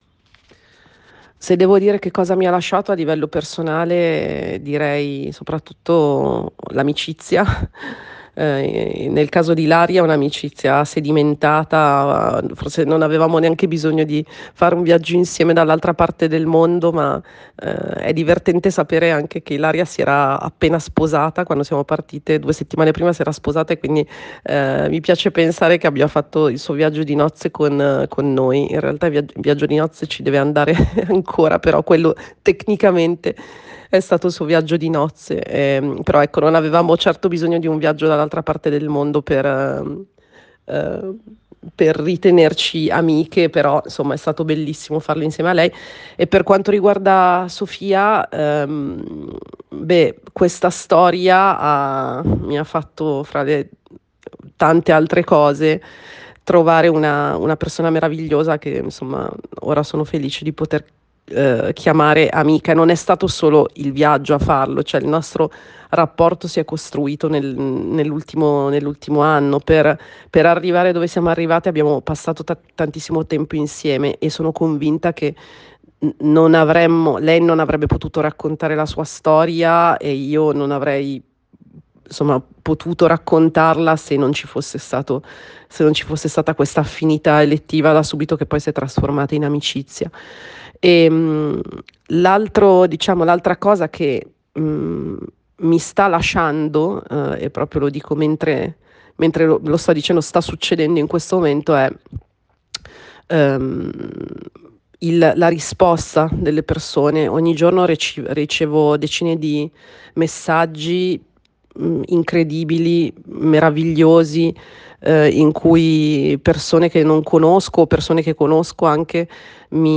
audio-intervista